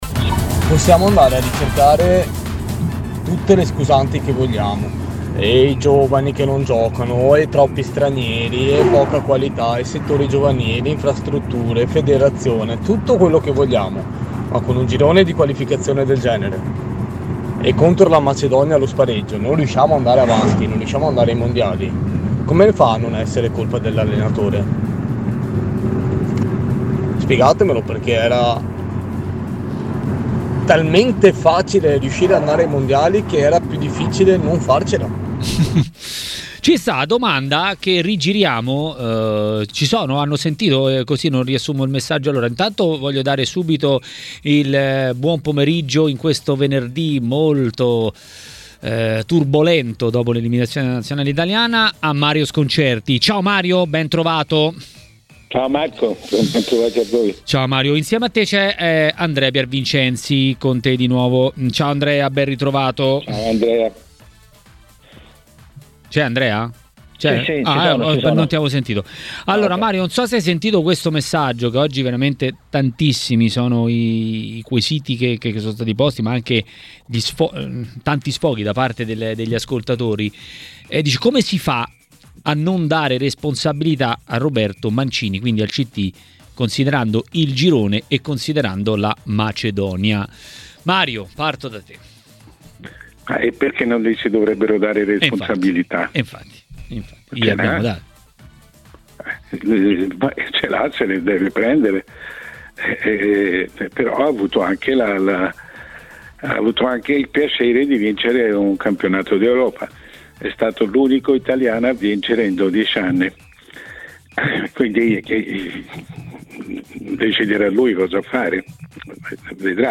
A TMW Radio, durante Maracanà, il direttore Mario Sconcerti è intervenuto in merito al ko negli spareggi mondiali dell'Italia.